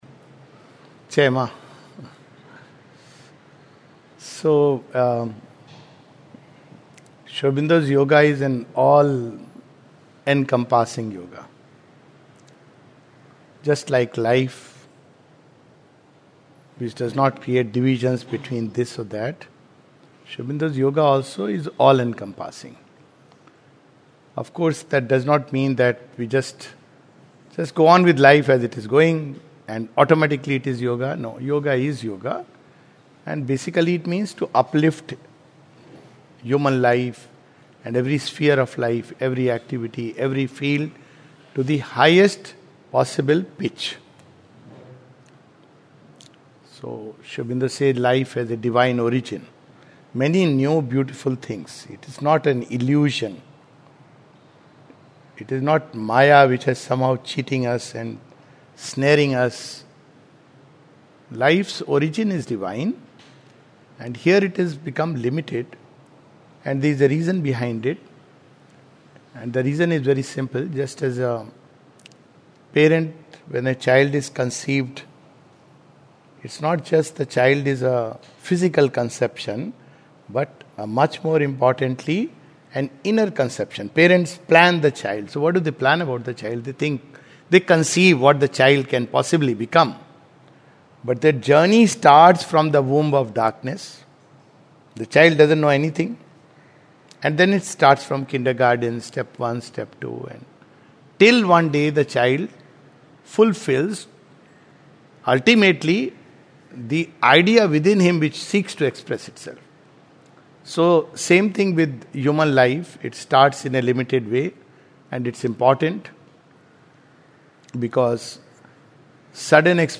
This talk at Matrimiketan, Sri Aurobindo Society, touches upon some of the mysteries of creation and the psychic being.